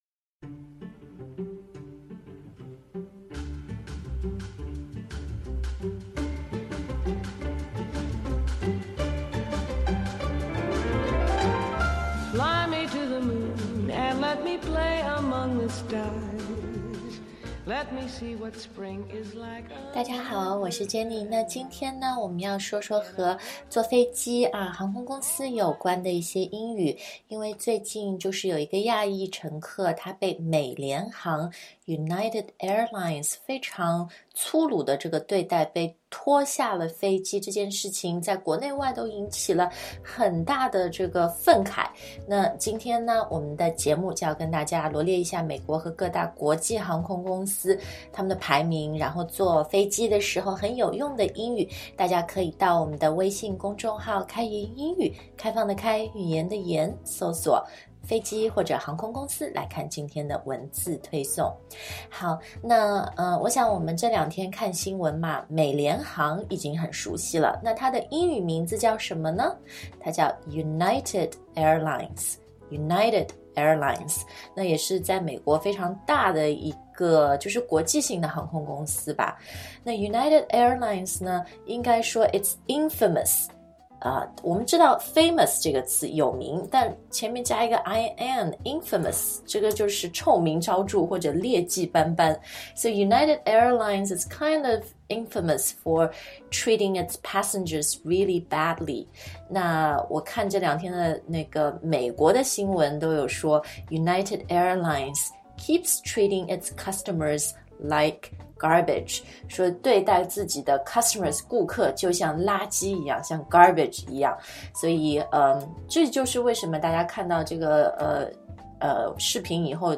节目音乐：Fly Me to the Moon by Julie London
Aisle的发音是[aɪl]， s不发音